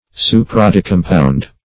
Search Result for " supradecompound" : The Collaborative International Dictionary of English v.0.48: Supradecompound \Su`pra*de*com"pound\, a. (Bot.)